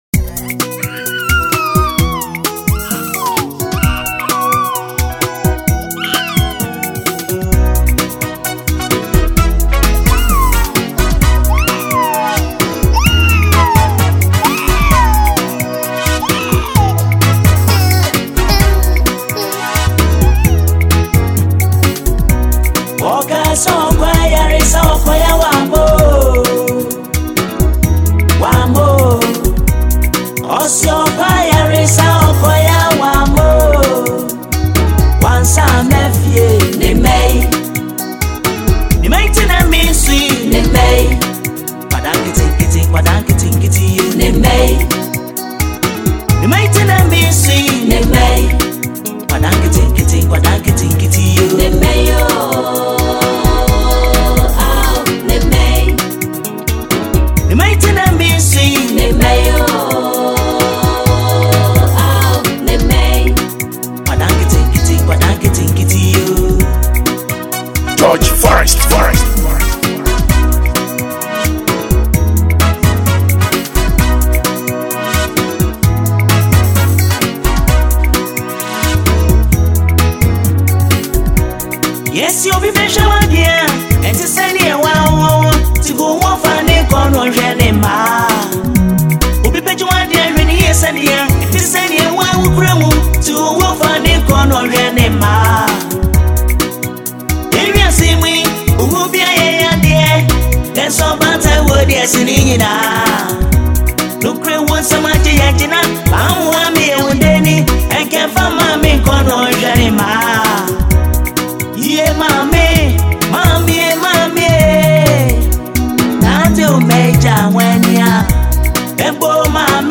a Ghanaian highlife artist
and this is a solo tune.